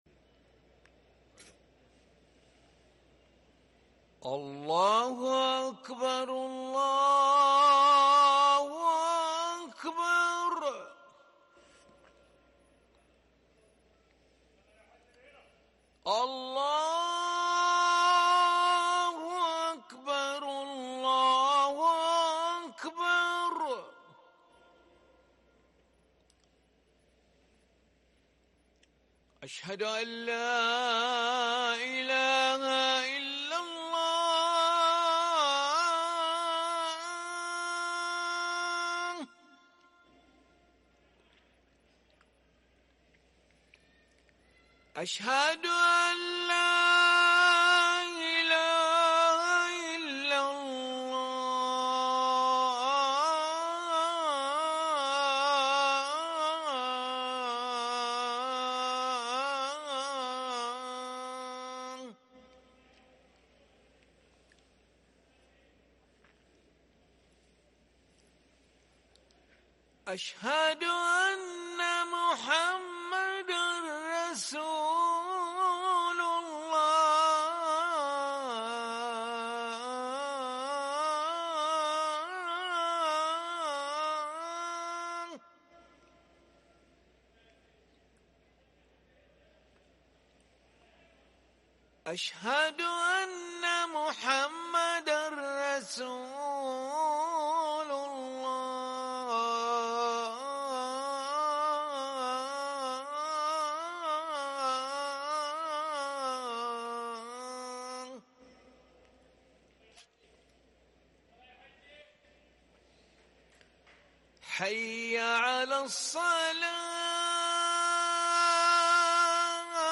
اذان العشاء للمؤذن علي ملا الاحد 2 محرم 1444هـ > ١٤٤٤ 🕋 > ركن الأذان 🕋 > المزيد - تلاوات الحرمين